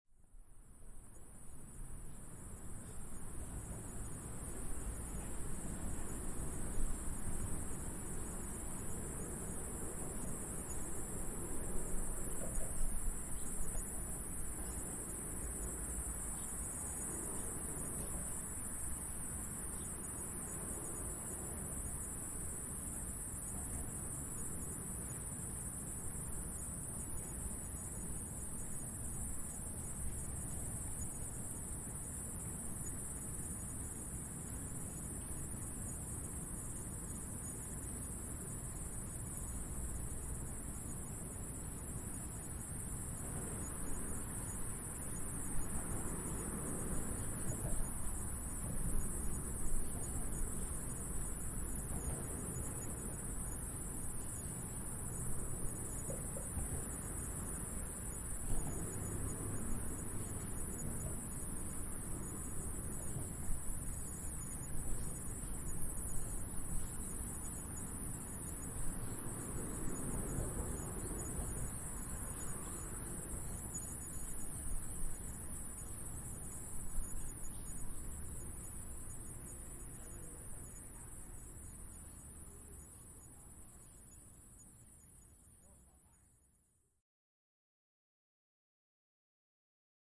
Animal Sounds Soundboard89 views